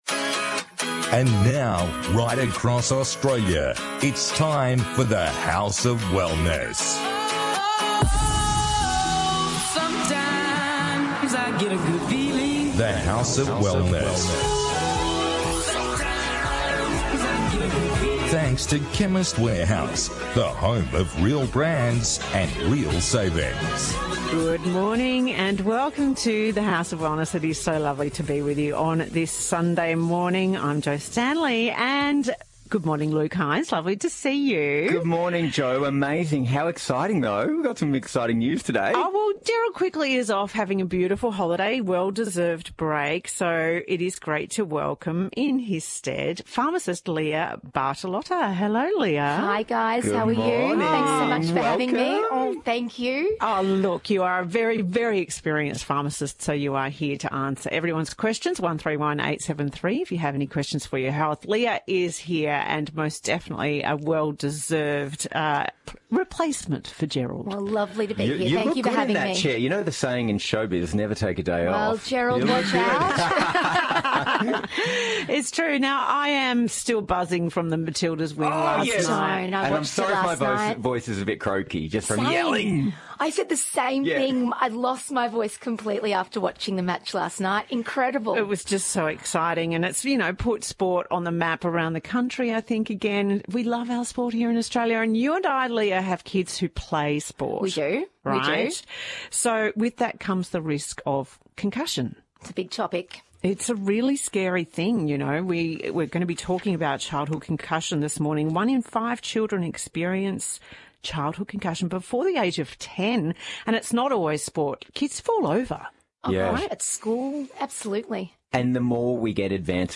On this week’s The House of Wellness radio show: